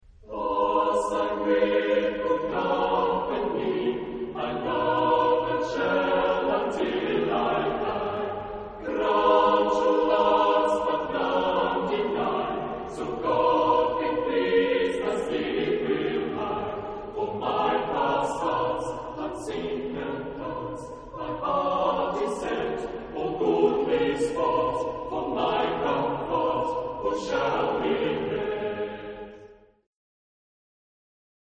Genre-Style-Forme : Chanson galante ; Renaissance ; Profane
Type de choeur : SSAATTBB  (8 voix mixtes )
Tonalité : sol mineur